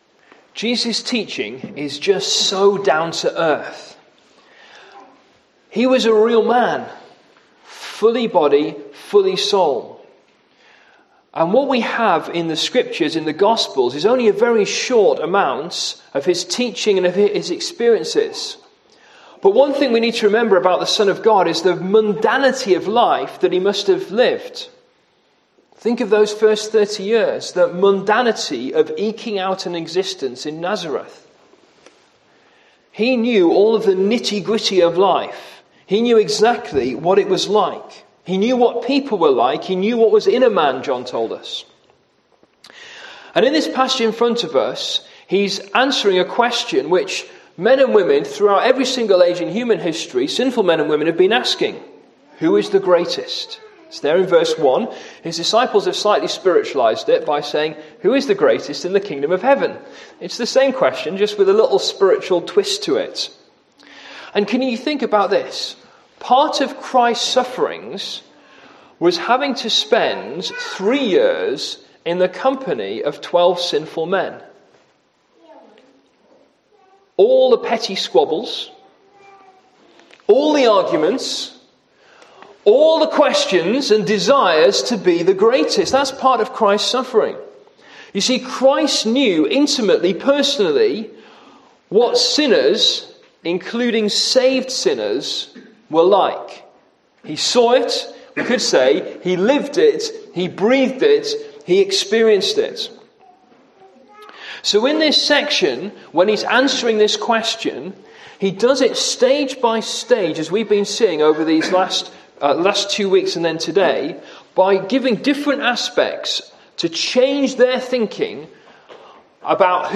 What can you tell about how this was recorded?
Service Type: AM